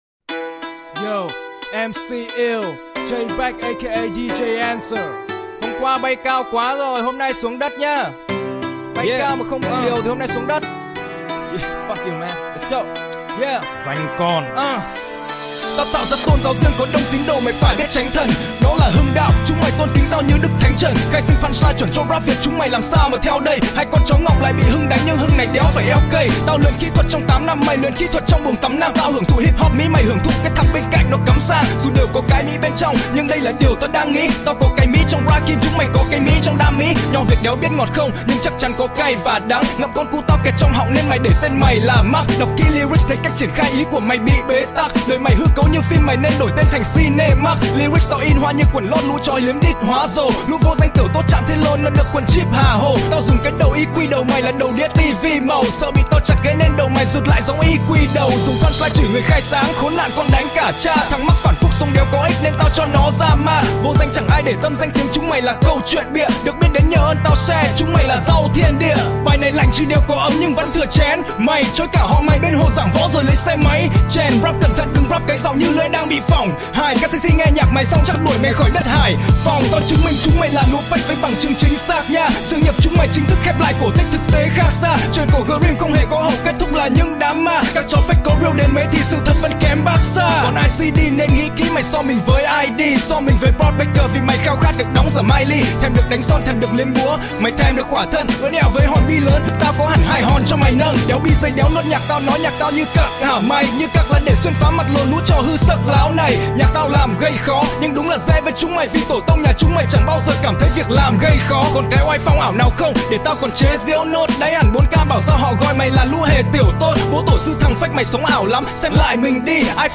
Sách nói | Truyện cổ Grim